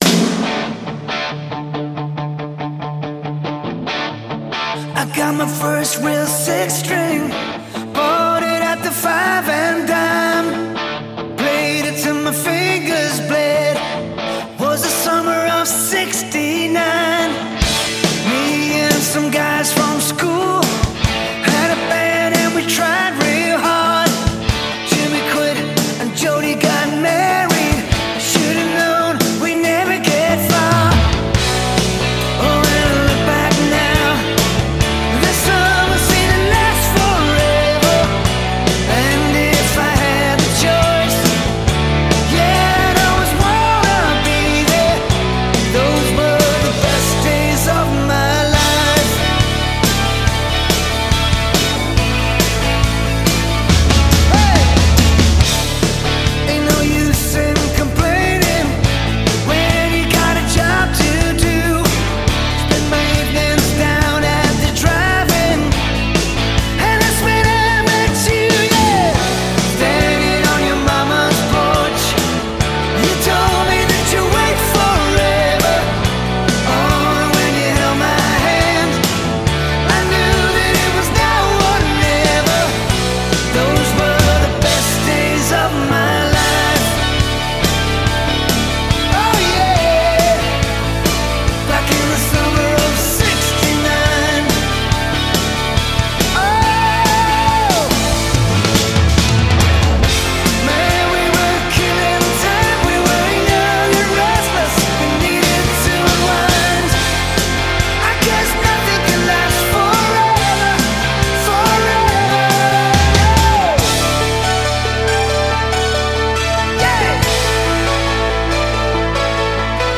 Track2_Pop.mp3